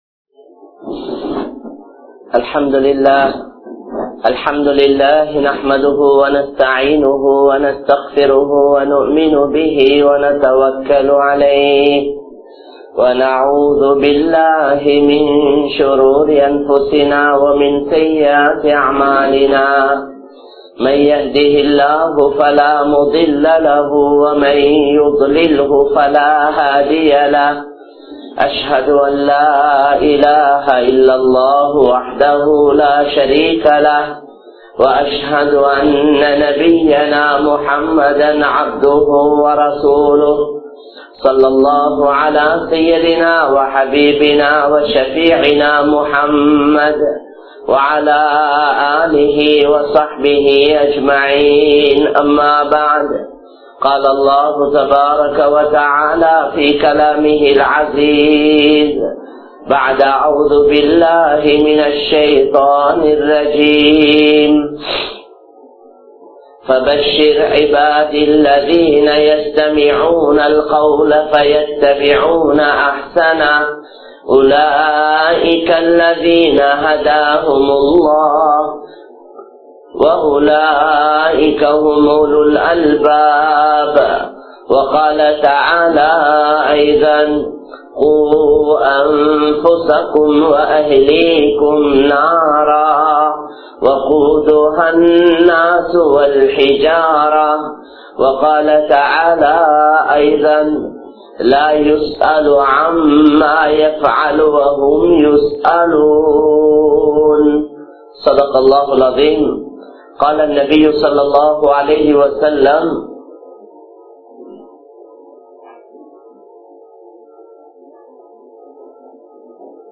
Petroarhalin Kadamaihal (பெற்றோர்களின் கடமைகள்) | Audio Bayans | All Ceylon Muslim Youth Community | Addalaichenai